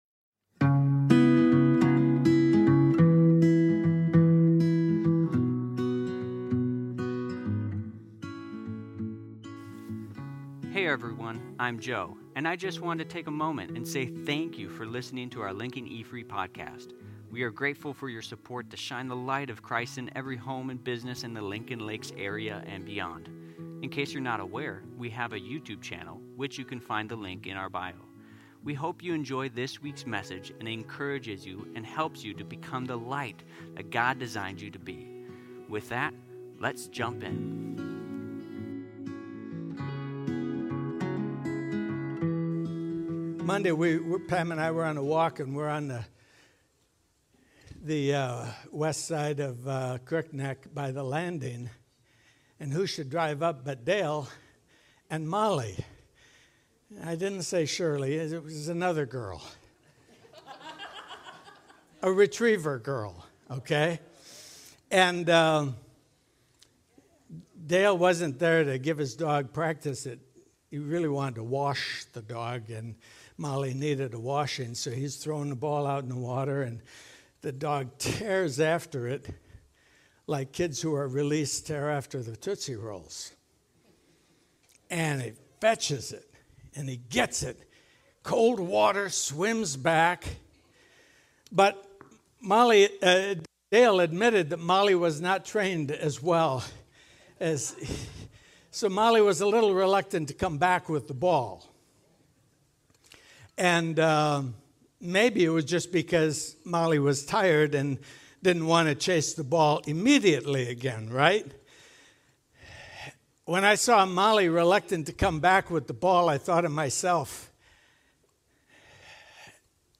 These series of talks will draw out truth from the Gospel of Luke.